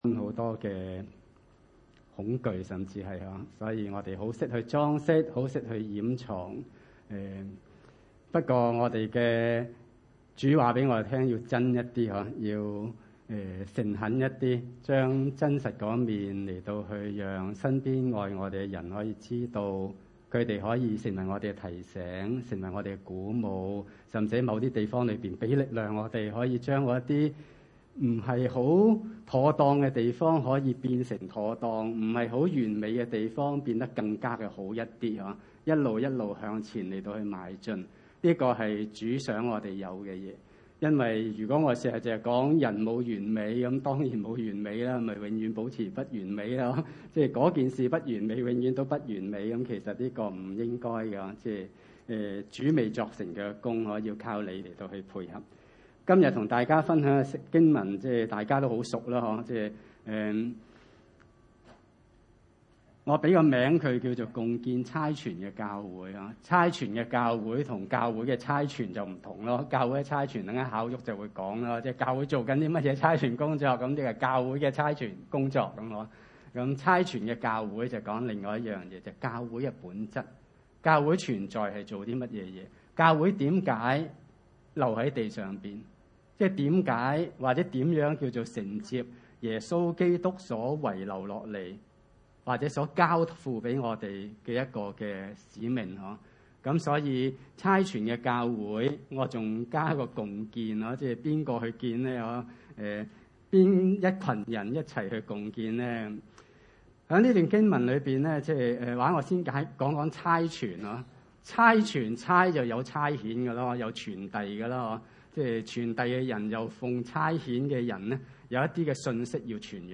馬太福音28：16-20 崇拜類別: 主日午堂崇拜 16.